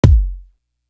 Shootem Up Kick.wav